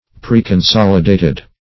Preconsolidated \Pre`con*sol"i*da`ted\